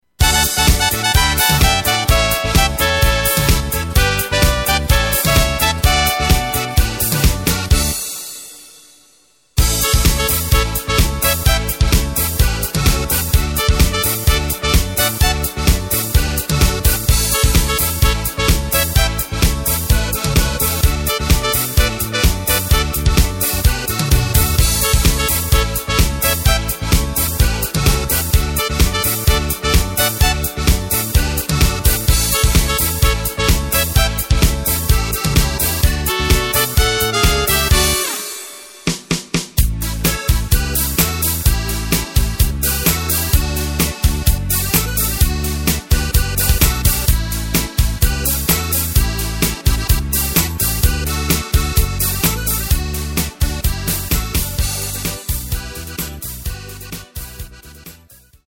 Takt:          4/4
Tempo:         128.00
Tonart:            D
Schlager aus dem Jahr 1994!